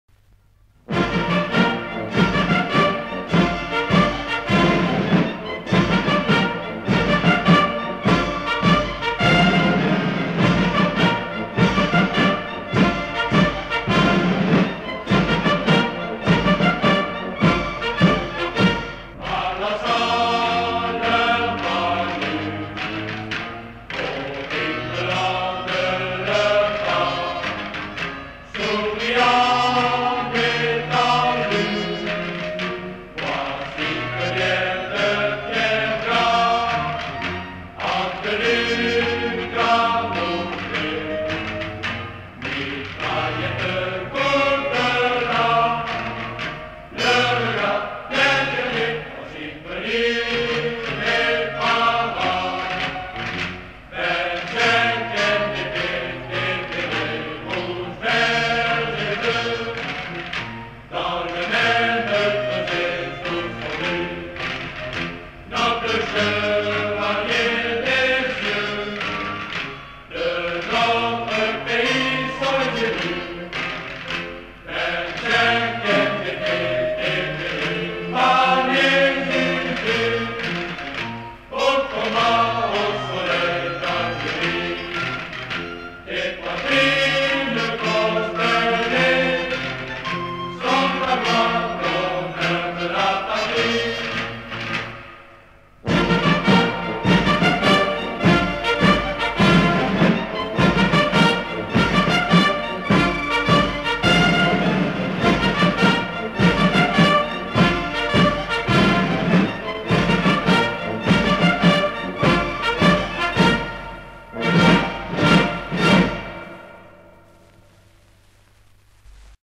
MARCHE-DE-LA-25e-DIVISION-PARACHUTISTE.mp3